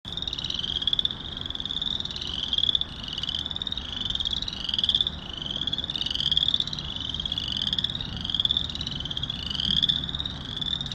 A Boreal Chorus Frog (Pseudacris maculata) sounds like someone running their finger across the teeth of a comb.
Boreal_chorus-1.m4a